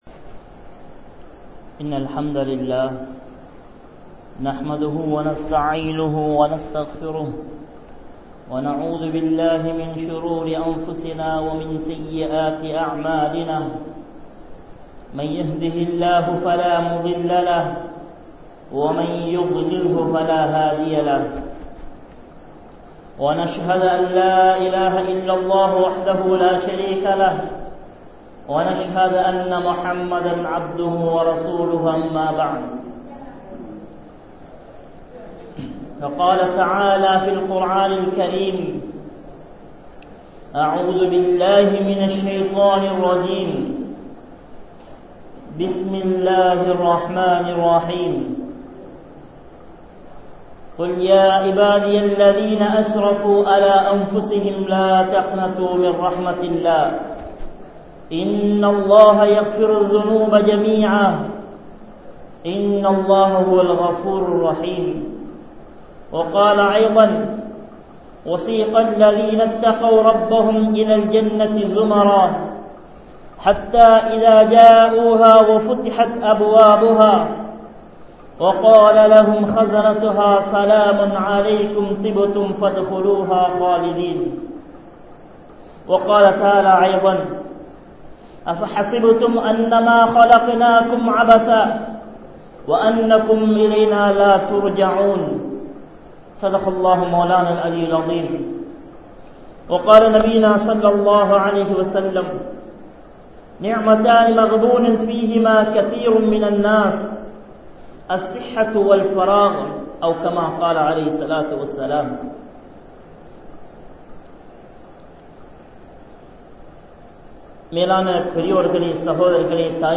Manithan Padaikkap Pattathan Noakkam (மனிதன் படைக்கப்பட்டதன் நோக்கம்) | Audio Bayans | All Ceylon Muslim Youth Community | Addalaichenai
Masjithur Ravaha